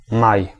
Ääntäminen
Synonyymit arbre de mai Ääntäminen France (Paris): IPA: [ɑ̃ mɛ] Tuntematon aksentti: IPA: /mɛ/ IPA: /me/ Haettu sana löytyi näillä lähdekielillä: ranska Käännös Ääninäyte Substantiivit 1. maj {m} Suku: m .